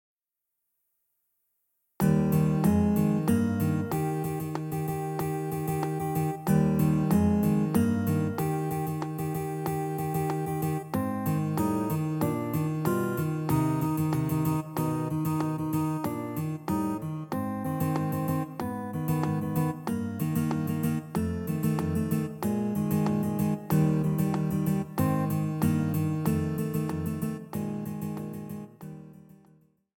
Recueil pour Clarinette